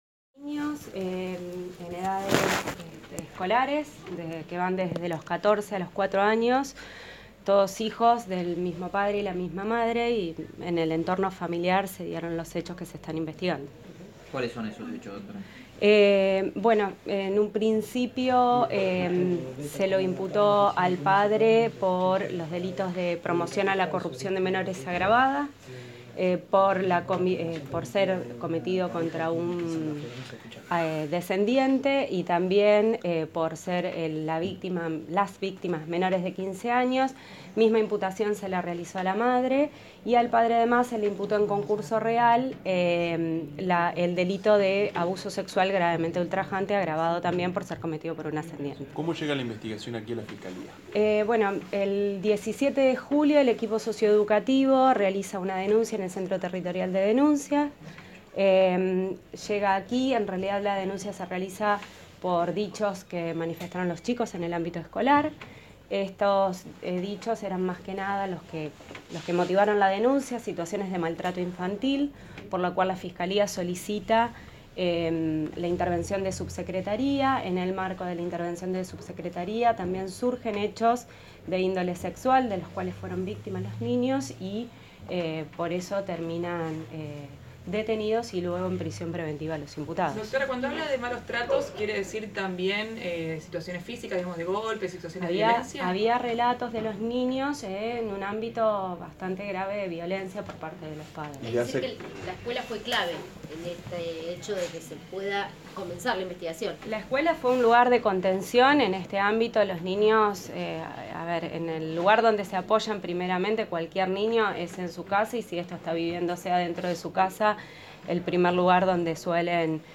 Escucha la palabra de la Fiscal Celeste Minniti: